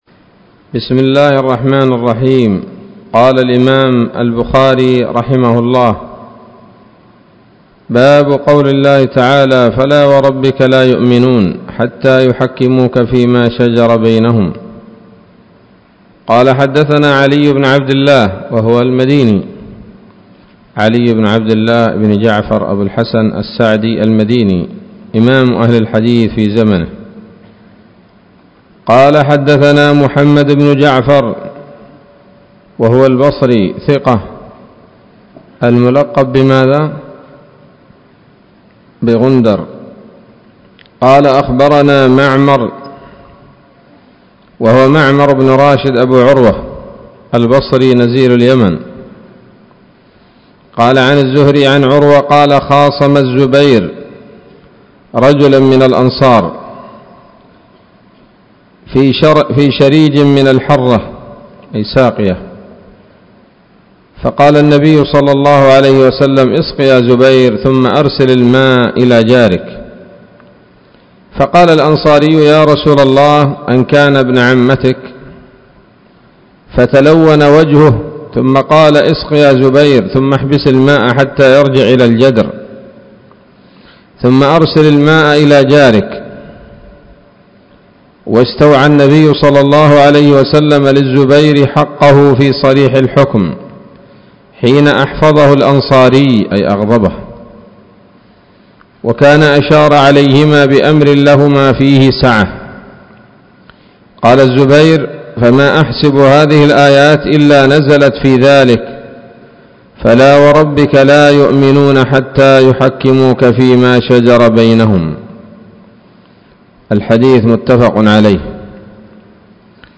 الدرس الثاني والسبعون من كتاب التفسير من صحيح الإمام البخاري